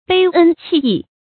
背恩棄義 注音： ㄅㄟˋ ㄣ ㄑㄧˋ ㄧˋ 讀音讀法： 意思解釋： 指辜負別人對自己的恩義。